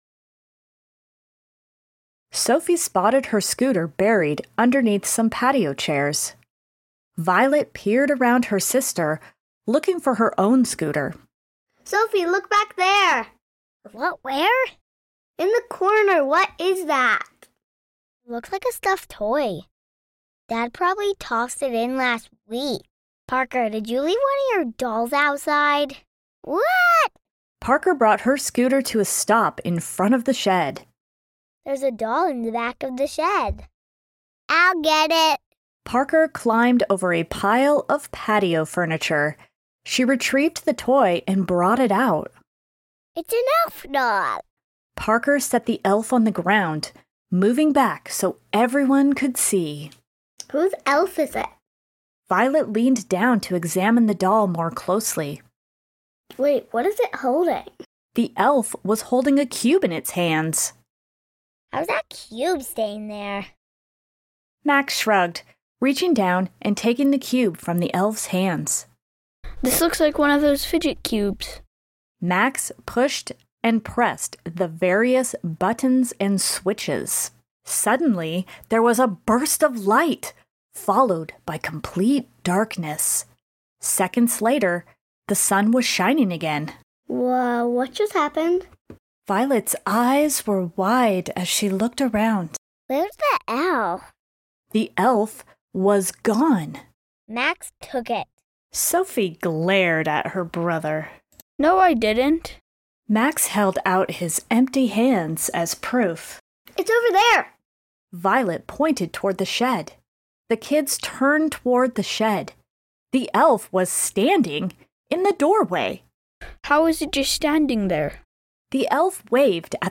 We Made some Audiobooks!!!